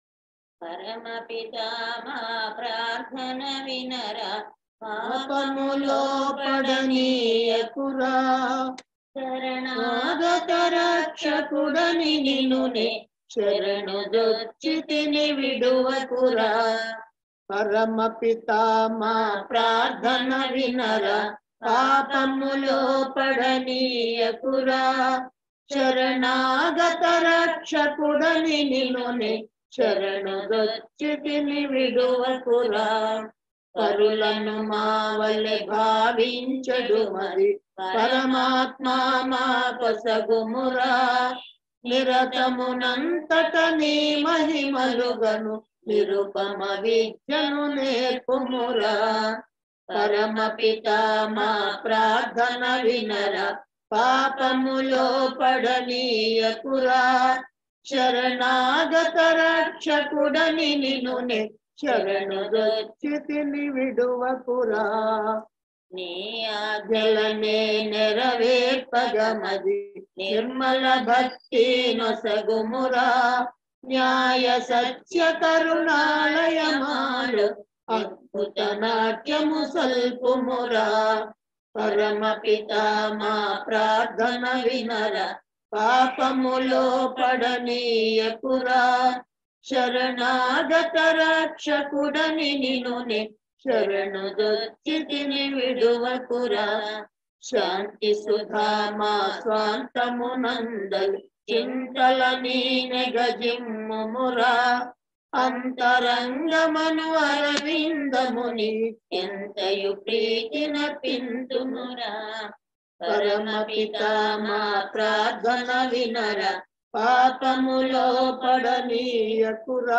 ధ్యాన సమయమునకు కీర్తన